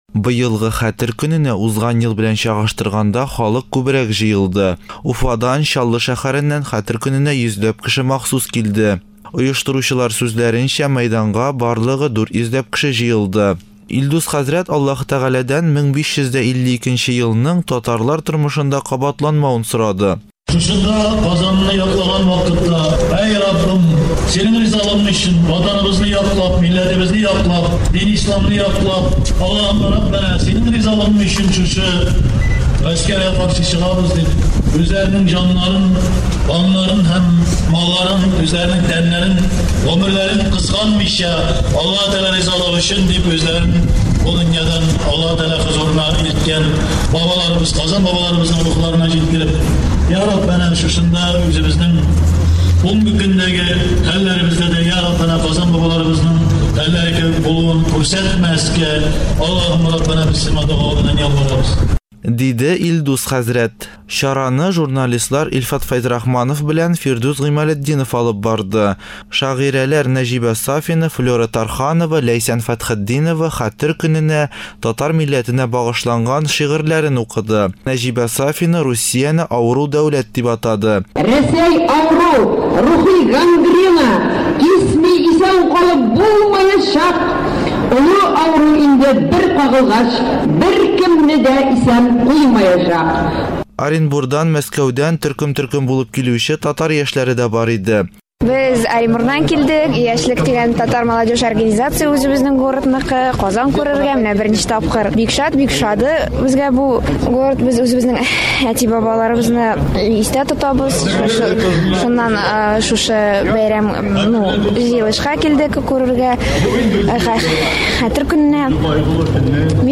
Хәтер көненнән репортаж